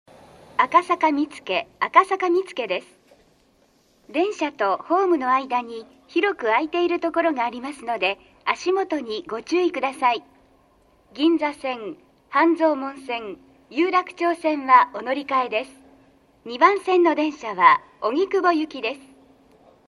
B線ホームで収録すると、真上にA線の線路があるのでよくガタンゴトンと被ります・・。
接近放送